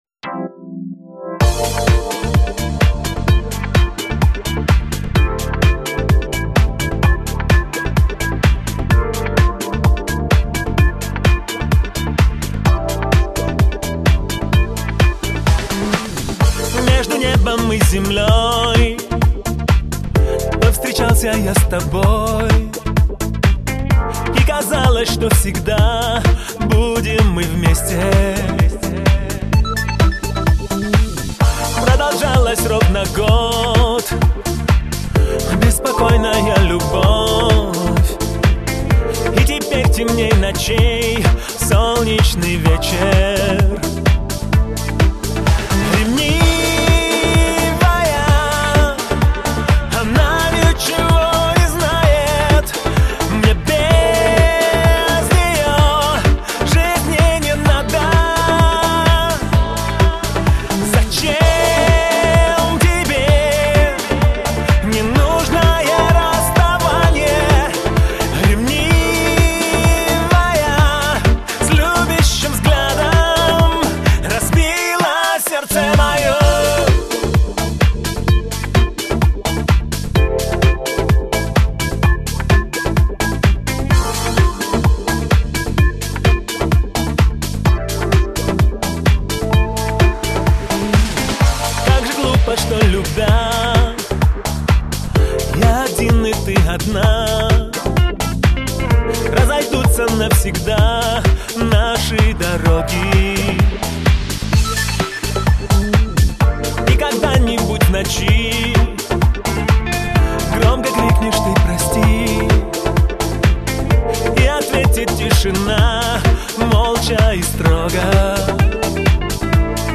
Очень прошу, угадайте пожалуйста несколько инструментальных мелодий.